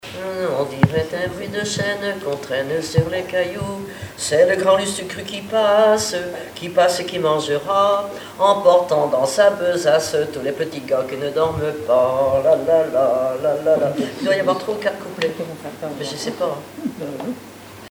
Chansons, formulettes enfantines
Pièce musicale inédite